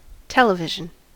television: Wikimedia Commons US English Pronunciations
En-us-television.WAV